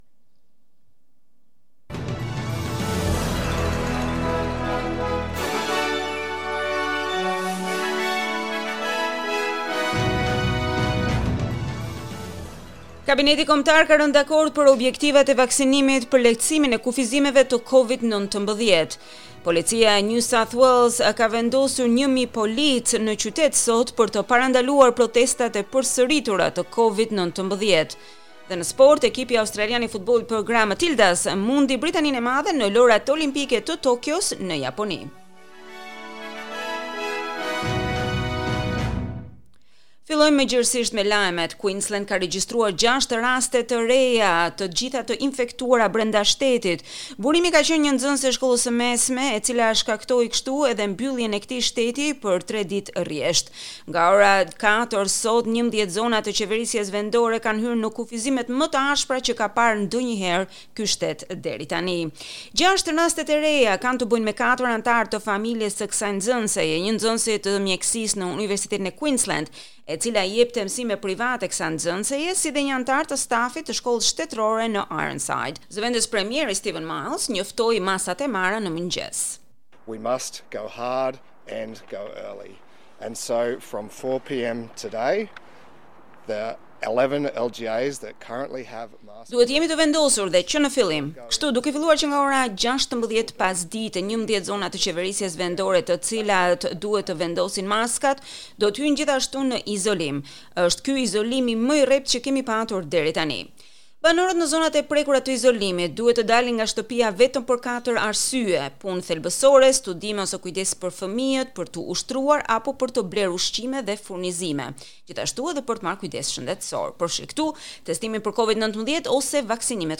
SBS News Bulletin in Albanian - 31 July 2021